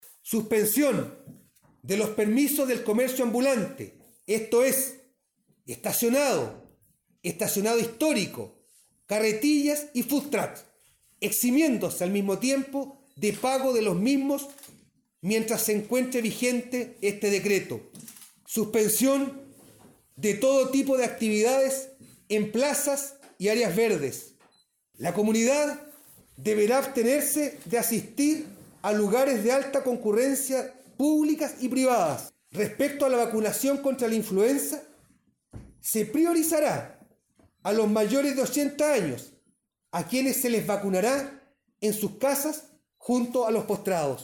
El alcalde de Castro Juan Eduardo Vera detalló algunos de los puntos y los acuerdos adoptados por los concejales, señalando en uno de ellos que a partir de este momento se restringe el funcionamiento del comercio ambulante.